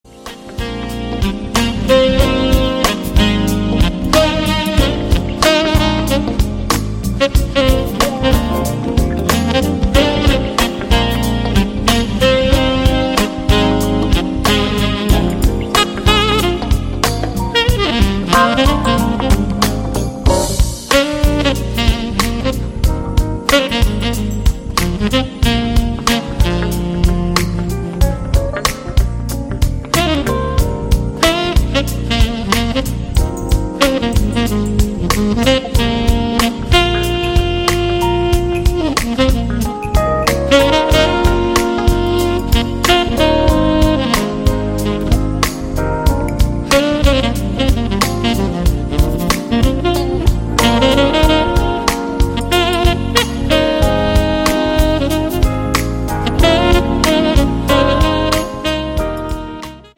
saxophonist
It is strong Smooth Jazz, enhanced by a full band
His playing adds warmth, depth and a little funkiness.